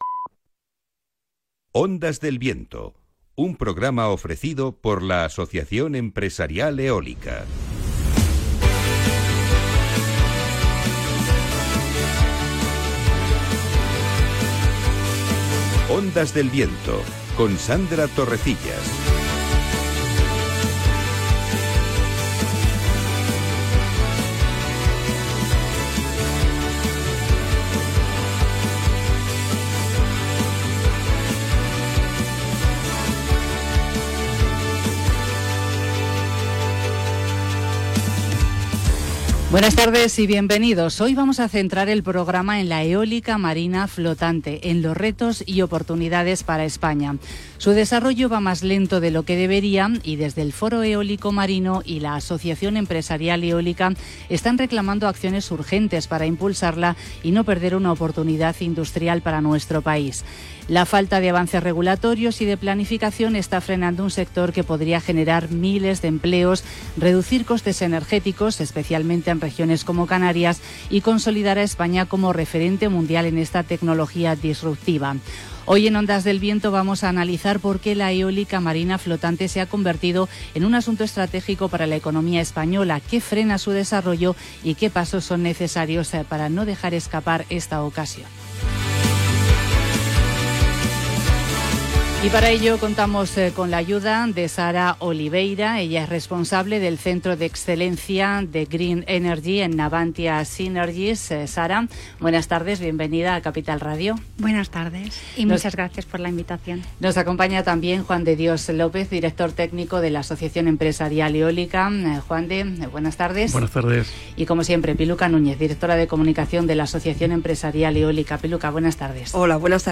🎙Hoy 28 de octubre hemos emitido un nuevo episodio de Ondas del Viento, el programa radiofónico del sector eólico en la emisora Capital Radio. 🎙Hemos hablado sobre la situación de la industria eólica marina flotante en España en la que el sector reclama acciones urgentes para impulsar un mercado que podría generar miles de empleos y transformar la economía española.